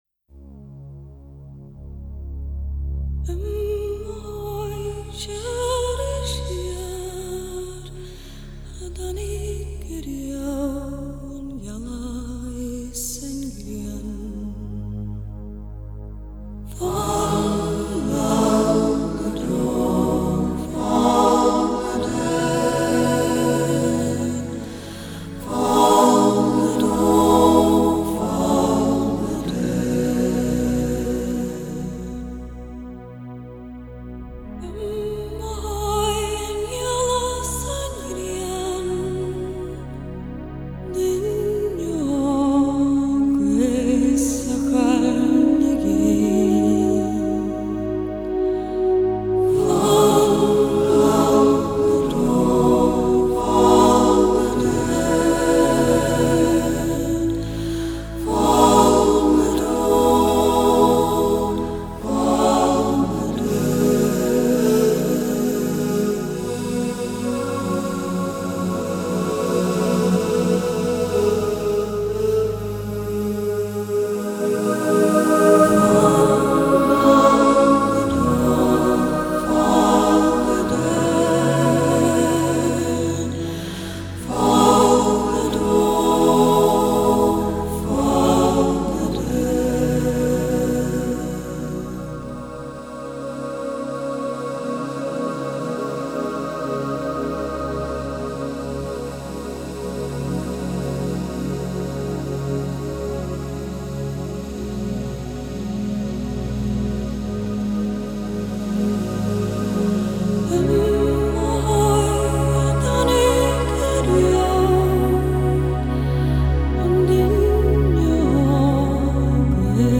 新生代流行跨界乐团